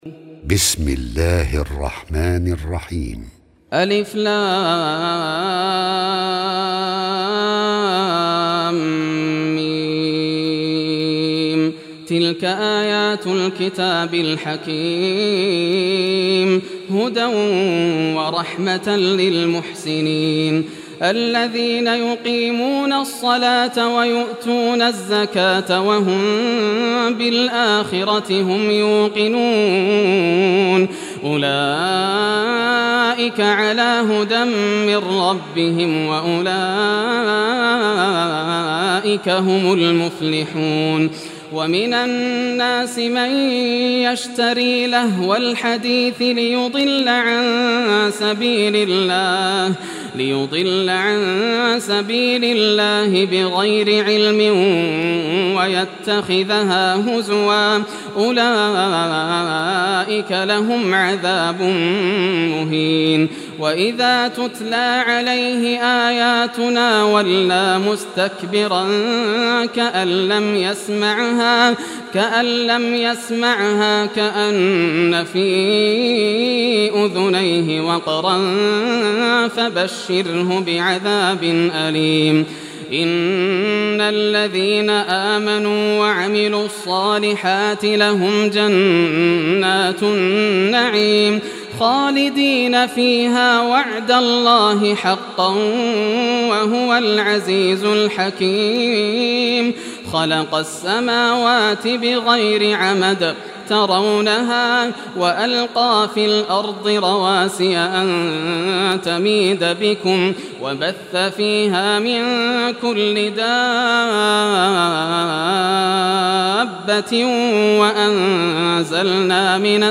Surah Luqman Recitation by Yasser al Dosari
Surah Luqman, listen or play online mp3 tilawat / recitation in Arabic in the beautiful voice of Sheikh Yasser al Dosari.